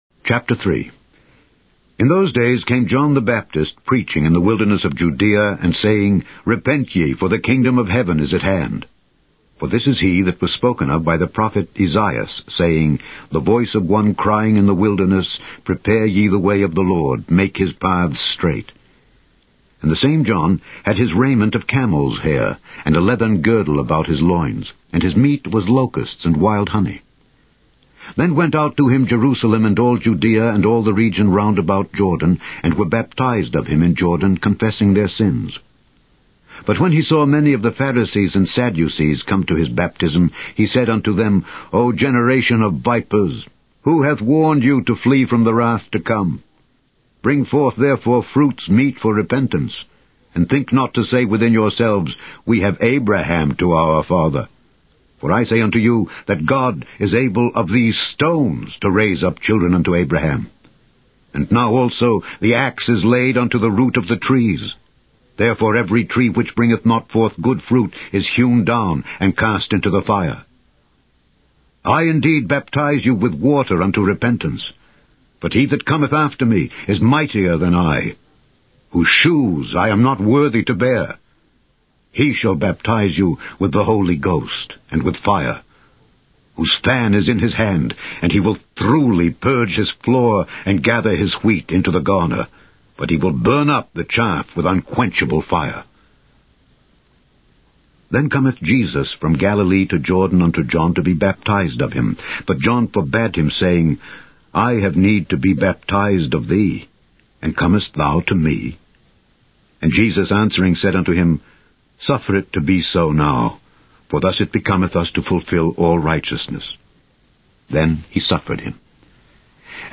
Directory Listing of ./Audio Books/The Holy Bible - Audio Bible - King James Version - Alexander Scourby - Voice of The Bible/ (SpiritMaji Files)